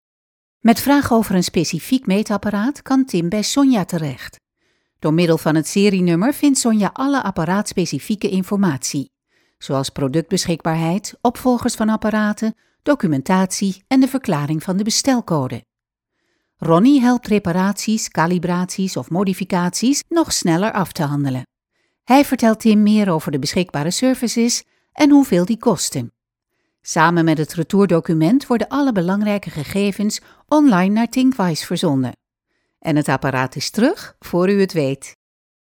A warm, convincing and reliable sound, but also, if required, fresh and happy.
Kein Dialekt
Sprechprobe: eLearning (Muttersprache):
dutch female voice over talent.